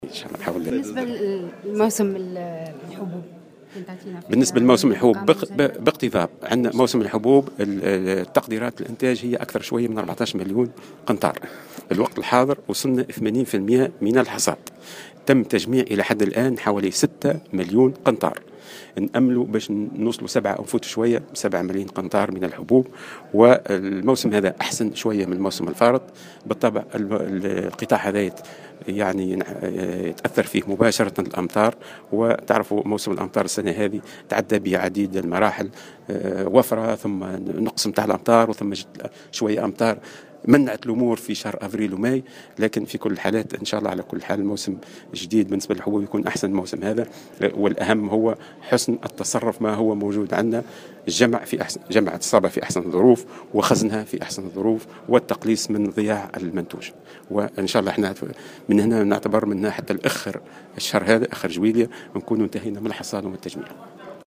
Lors d'une conférence de presse, le ministre a indiqué qu'environ 1.4 millions tonnes de céréales ont été produites durant la saison actuelle.